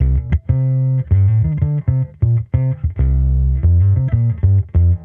Index of /musicradar/sampled-funk-soul-samples/95bpm/Bass
SSF_PBassProc2_95C.wav